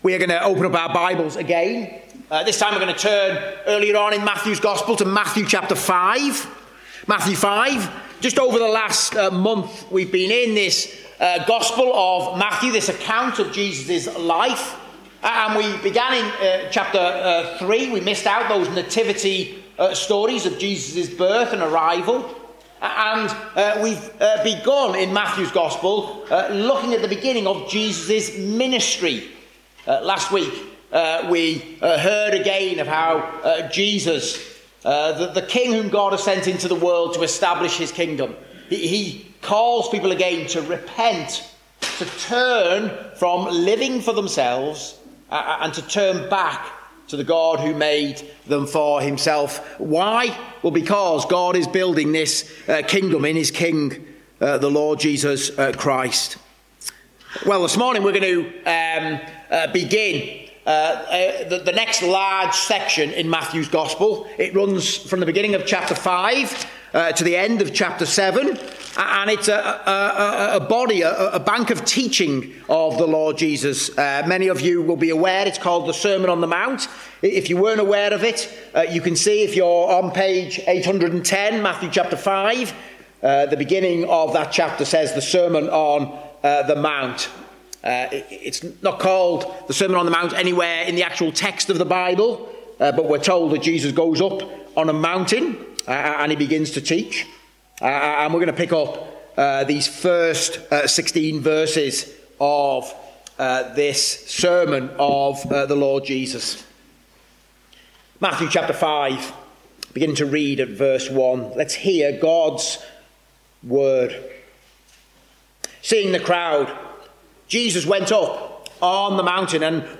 Matthew 5:1-16 Service Type: Preaching The beatitudes speak of the Lord Jesus enabling us to witness to him.